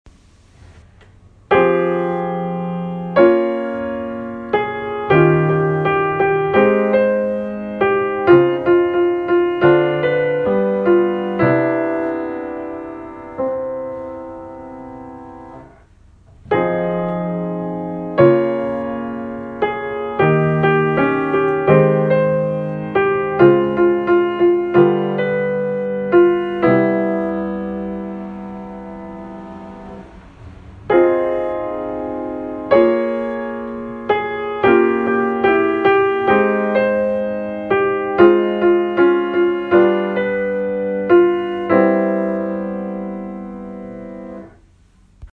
Still, here are three unornamented options (lots of “footballs”) that I’d stand behind.